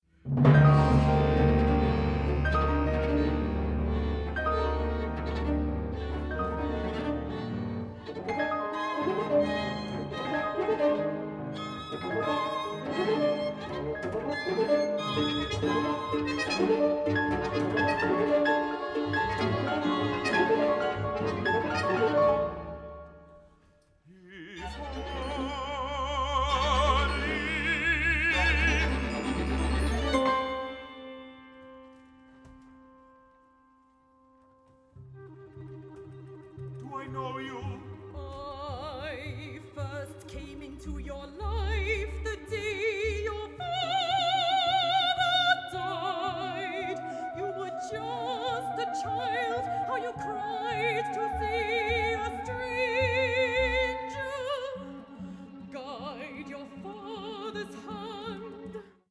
stereo (CD), stereo/5.1 (DVD)
baritone
mezzo-soprano
tenor
soprano
counter-tenor
flute
clarinet
trumpet
horn
percussion
harp
violin
viola
cello
double bass
piano
Opera in one act